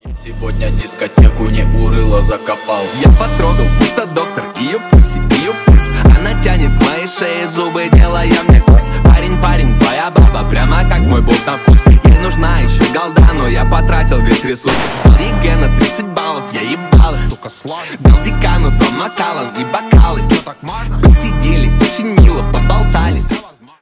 Ремикс
громкие